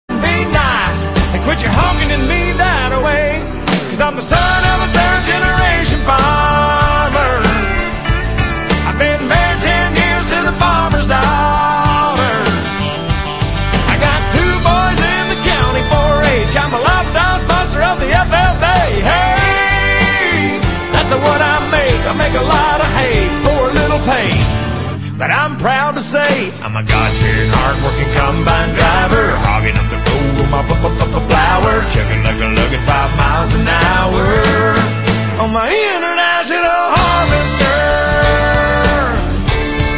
• Country Ringtones